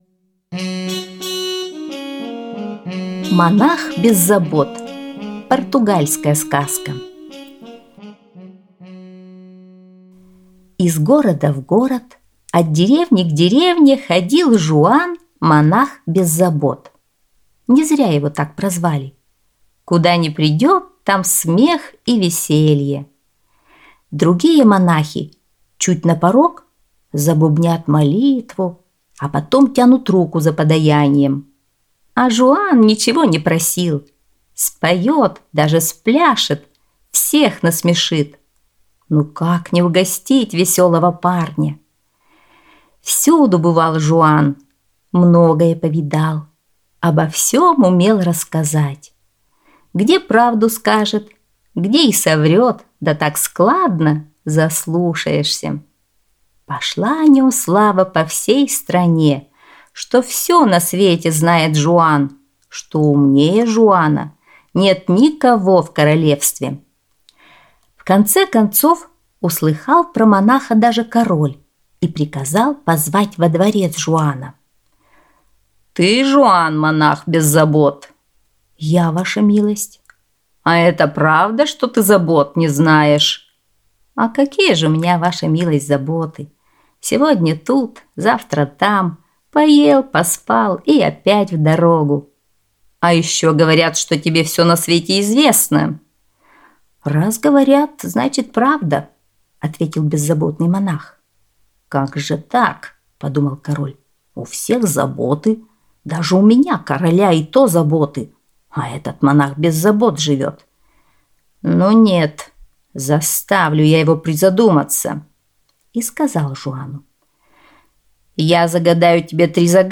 Аудиосказка «Монах без забот»